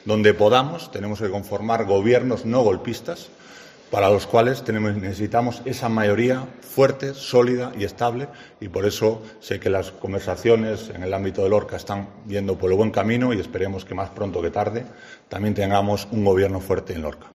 José Ángel Antelo, portavoz de VOX
Lo ha dicho en la sede de la patronal Ceclor, en un acto al que también ha acudido el alcalde, Fulgencio Gil, del PP, que no se ha pronunciado públicamente sobre este tema.